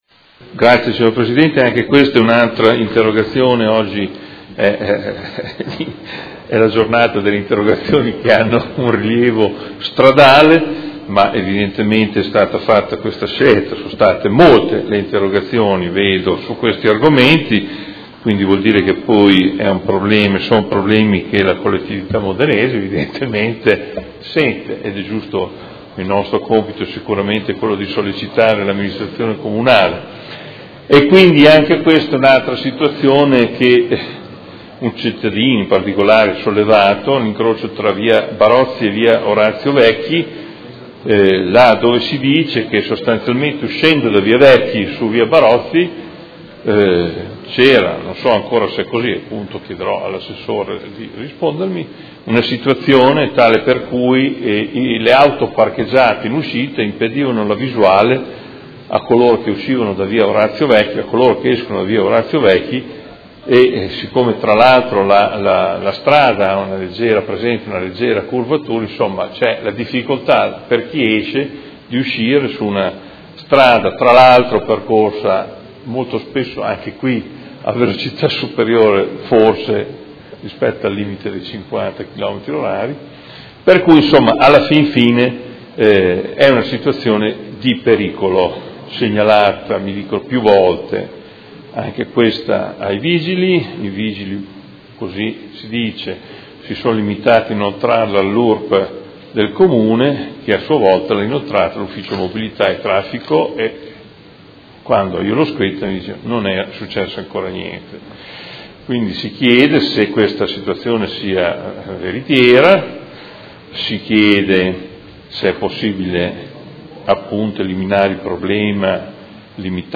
Seduta del 23/03/2017 Interrogazione del Consigliere Morandi (FI) avente per oggetto: Pericolo all’incrocio tra Via Barozzi e Via O. Vecchi